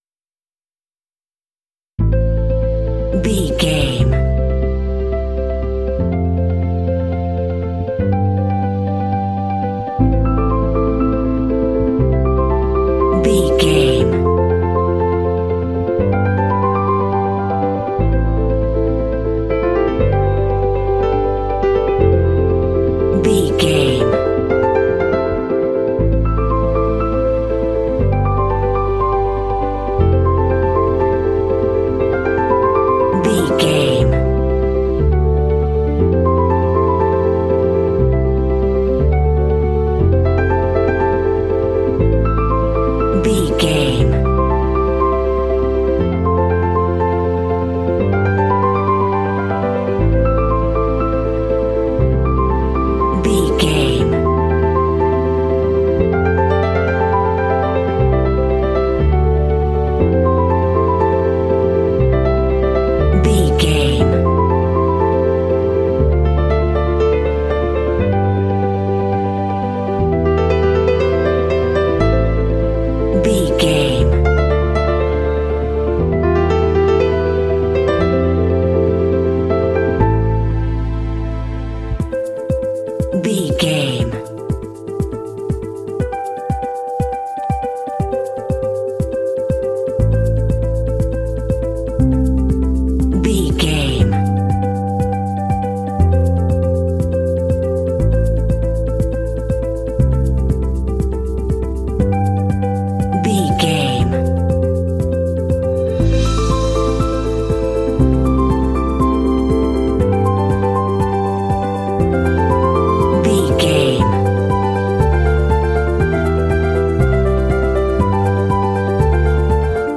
Aeolian/Minor
melancholy
contemplative
serene
peaceful
reflective
electric guitar
bass guitar
strings
drums
percussion
piano
ambient
contemporary underscore